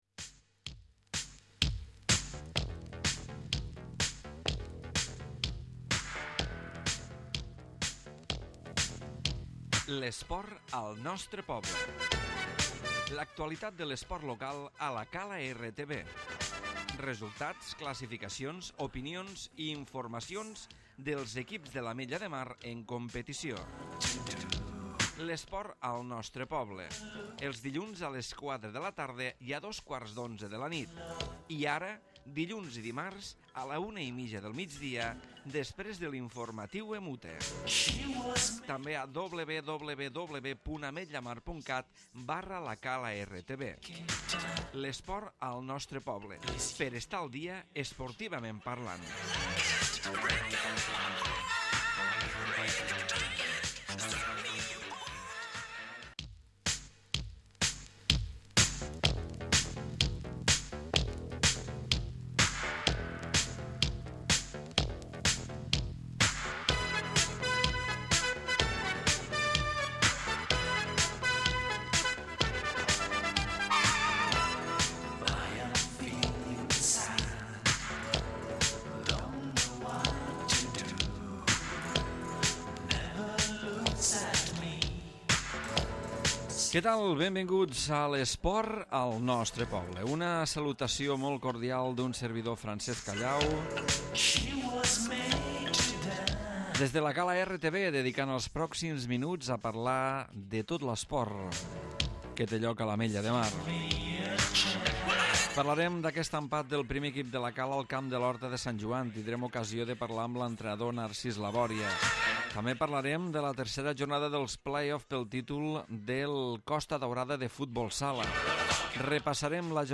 amb entrevista